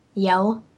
yell